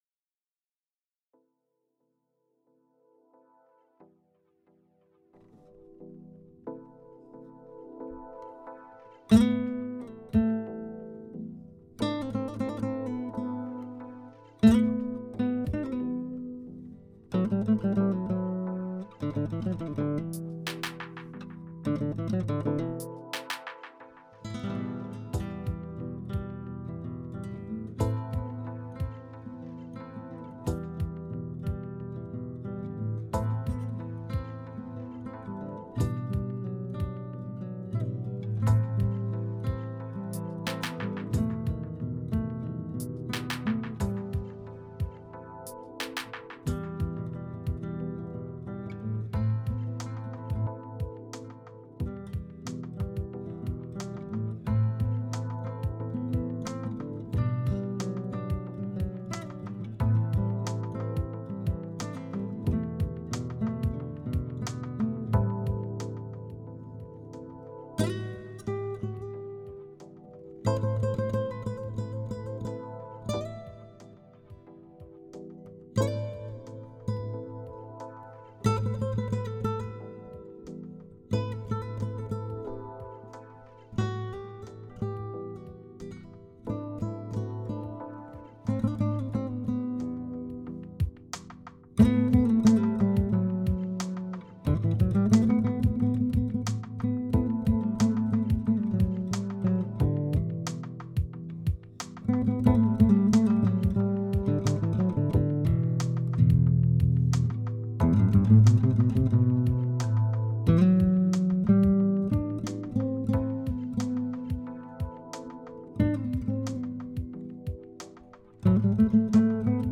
تم انتاج هذه المقطوعه بالكامل داخل استوديو الخاص في